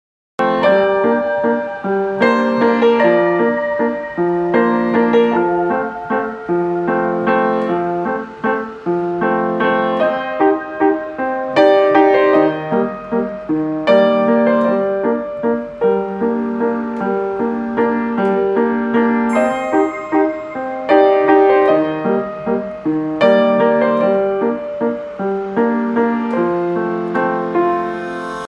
• Качество: 128, Stereo
красивые
спокойные
без слов
crossover
инструментальные
пианино
вальс
New Age